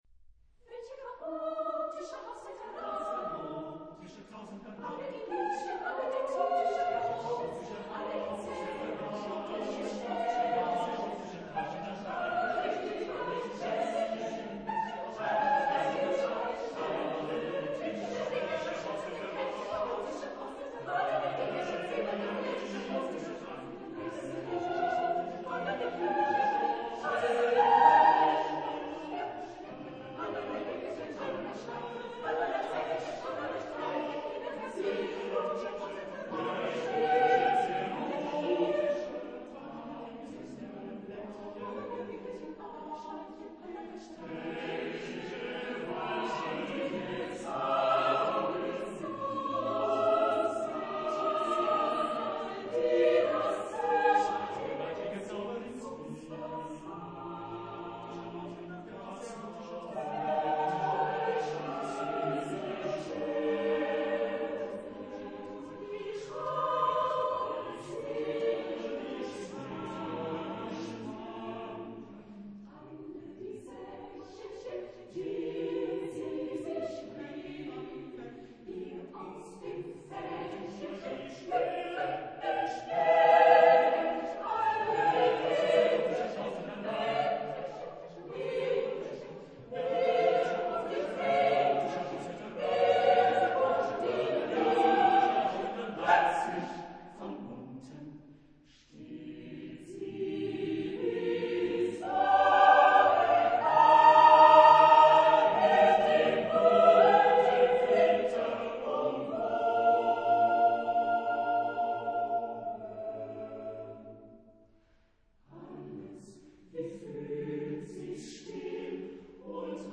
Genre-Style-Forme : Profane ; contemporain
Type de choeur : SSAATTBB  (8 voix mixtes )
Tonalité : mi bémol majeur